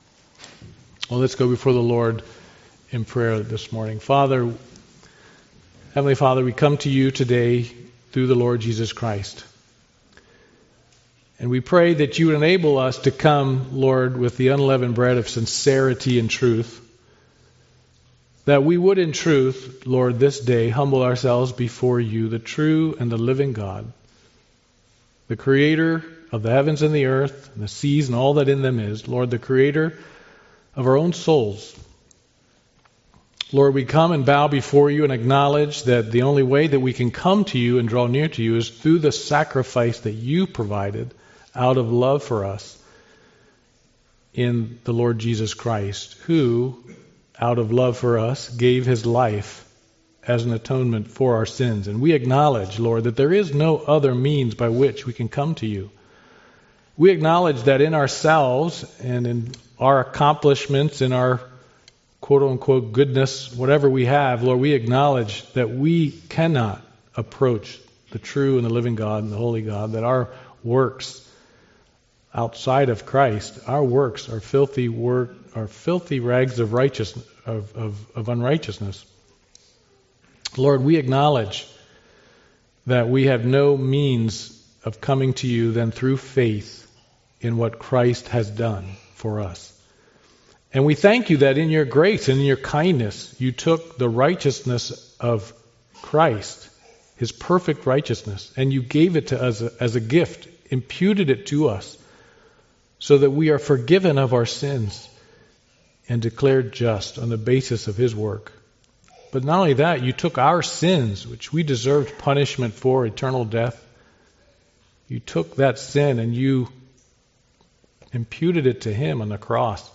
Passage: 2 Peter 1 Service Type: Sunday Morning Worship